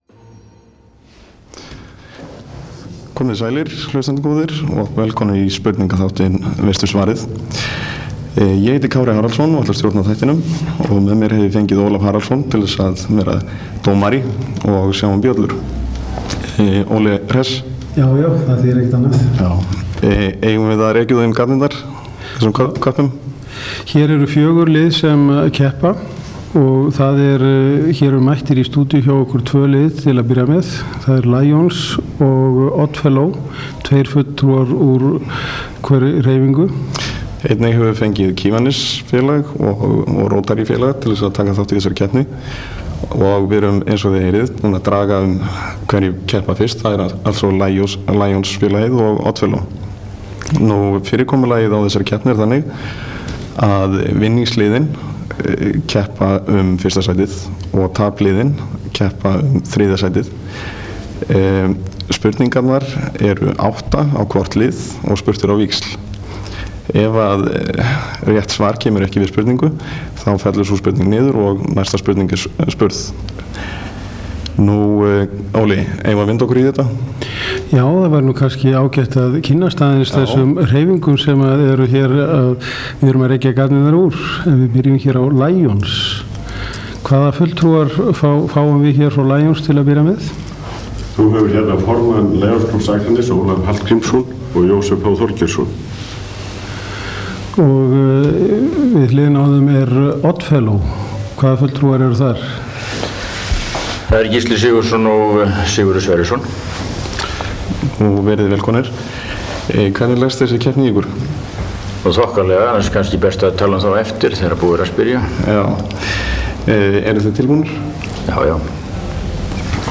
Spurningaþáttur með aðild félaga og klúbba á Akranei